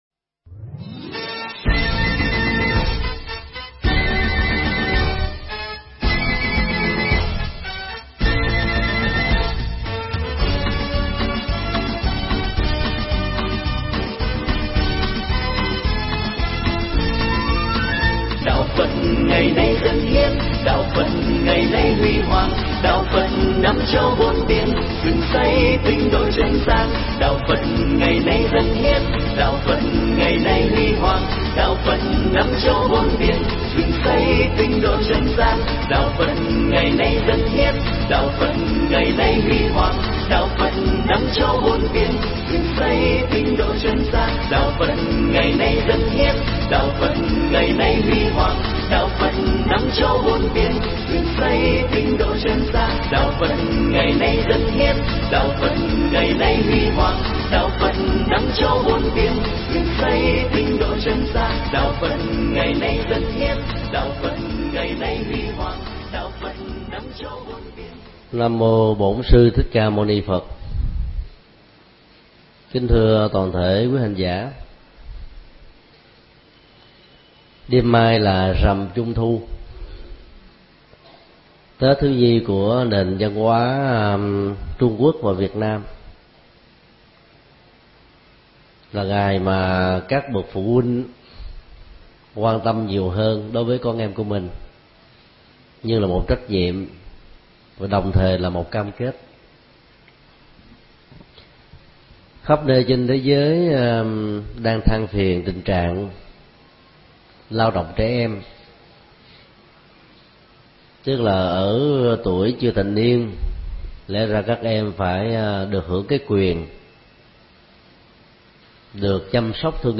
Mp3 Thuyết pháp Bát Chánh Đạo 3: Chánh Ngữ – Lời nói từ ái và xây dựng
tại chùa Giác Ngộ